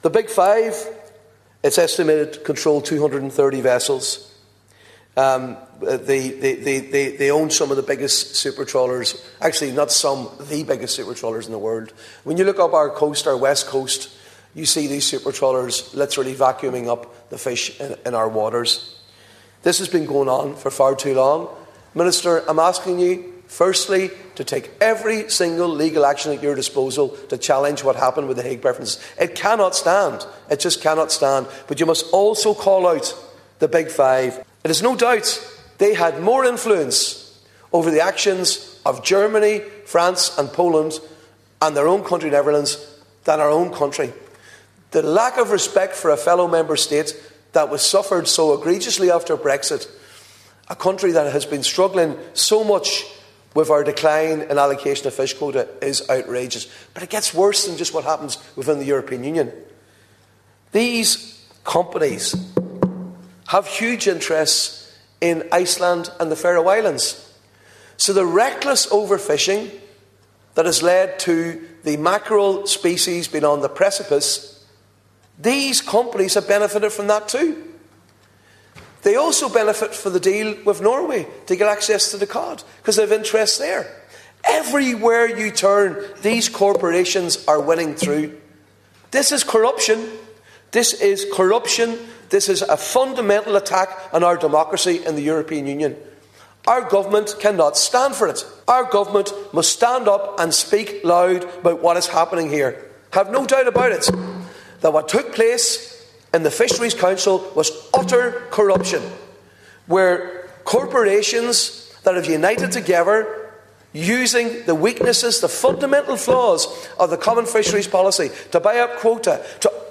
In the Dail, Deputy Padraig MacLochlainn said the actions of Germany, France, Poland and the Netherlands amounted to a betrayal of Ireland, and jeapordise the future of the fishing sector here.